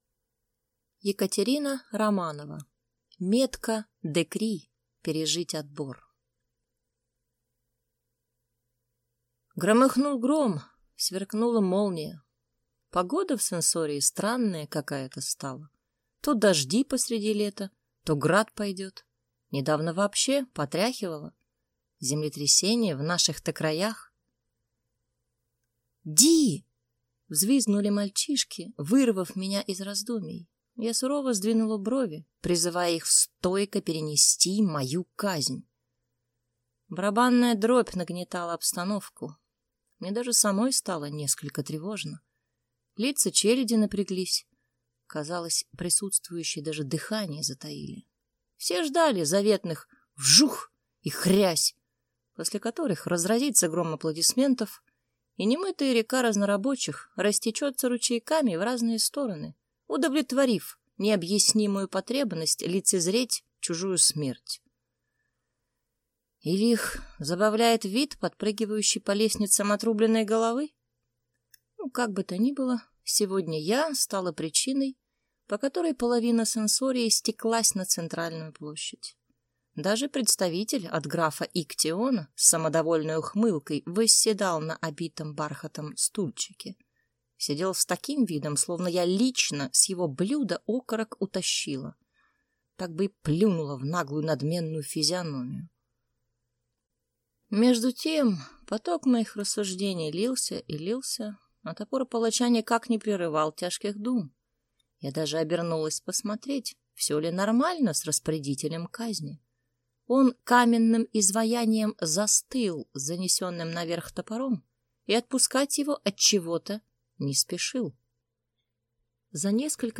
Аудиокнига Метка Де'кри: пережить отбор | Библиотека аудиокниг